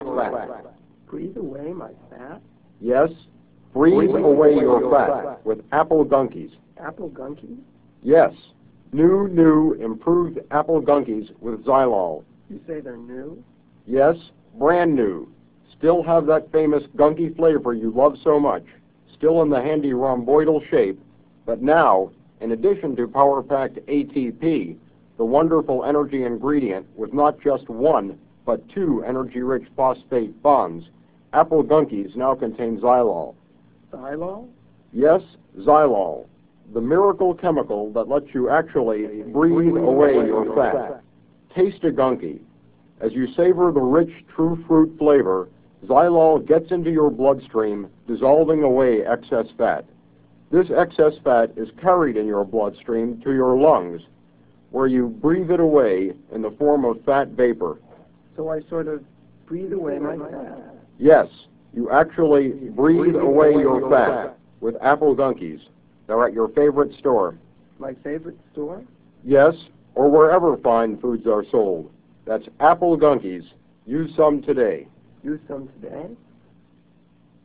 I decided it would be fun to run fake humorous commercials instead.
Breathe Away Your Fat parodied a style heard in some of the stupider ads of the time.